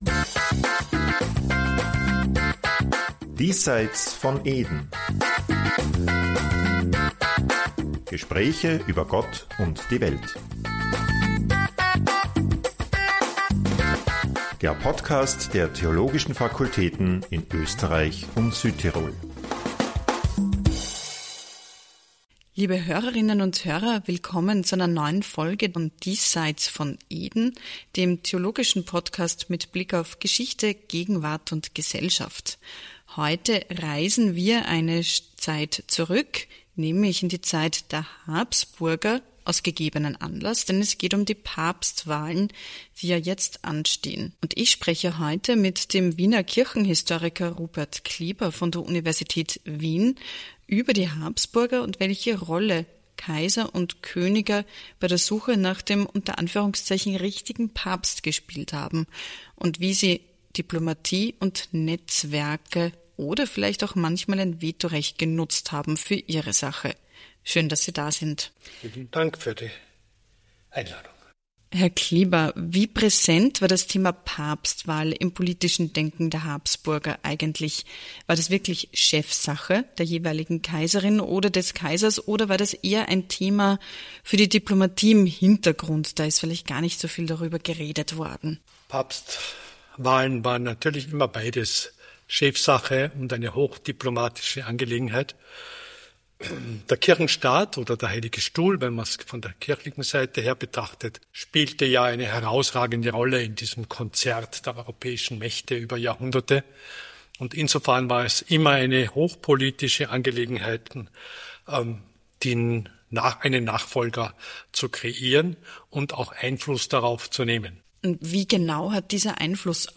Wie stark mischten die Habsburger bei Papstwahlen wirklich mit? Welche Rolle spielten Macht, Frömmigkeit und Politik – und was bedeutet das für das Konklave von heute? Ein Podcast über kaiserliche Strategien, vatikanische Realitäten – und die Frage, was davon bis heute nachwirkt.